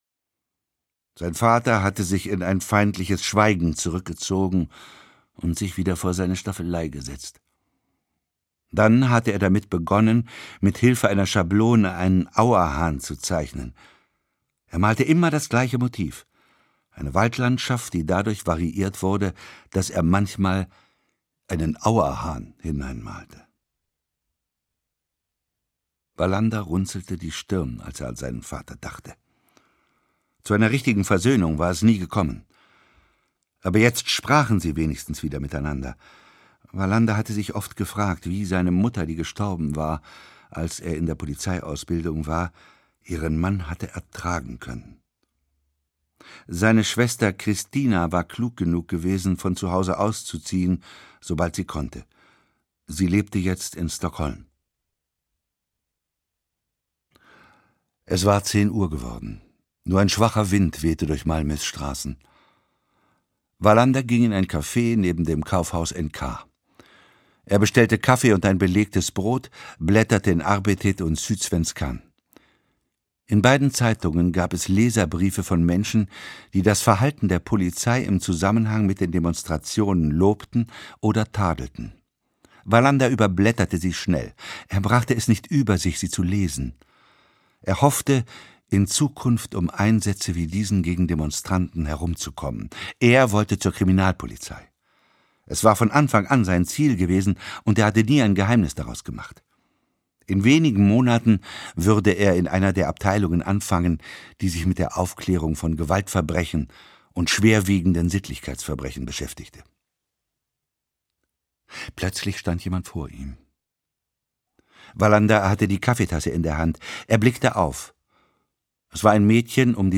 Wallanders erster Fall (Ein Kurt-Wallander-Krimi 1) - Henning Mankell - Hörbuch